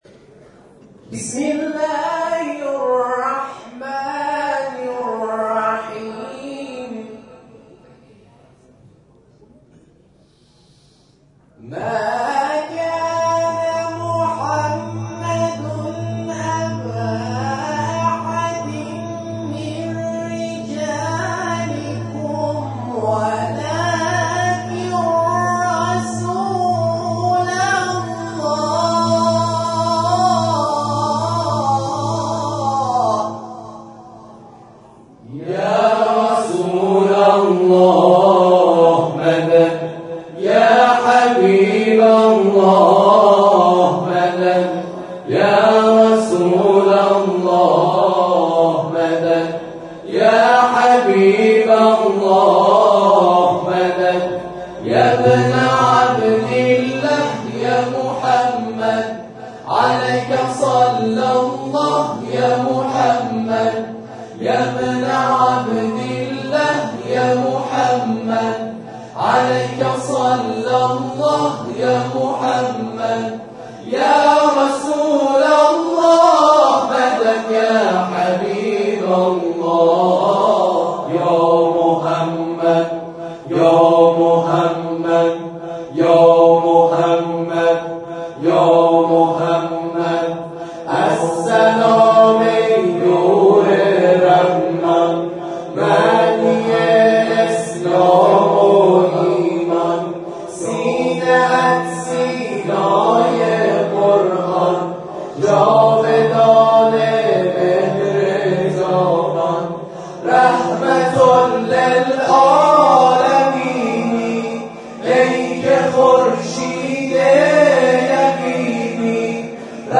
گروه همسرایی بیان نیز در پایان مراسم به اجرای برنامه‌های خود پرداختند که در ادامه ارائه می‌شود.
همسرایی بیان